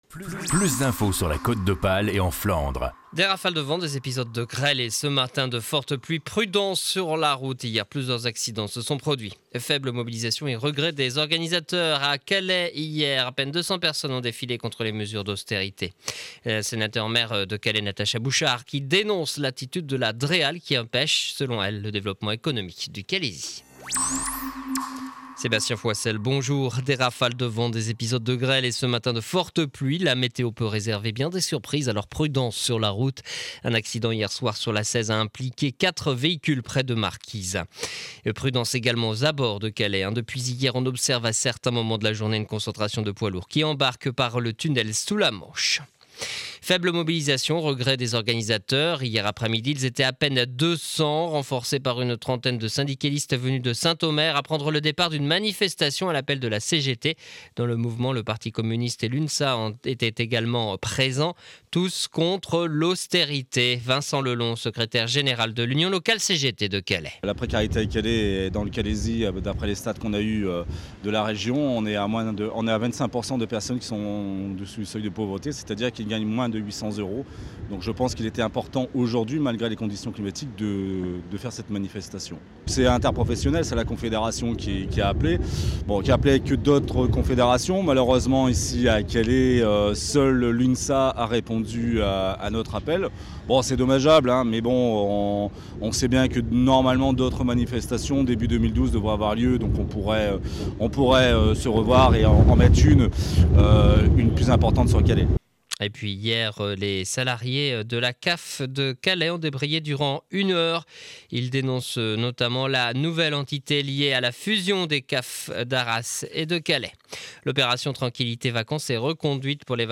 journal du mercredi 14 décembre édition de 7h30 à Calais